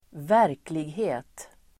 Uttal: [²v'är:klighe:t]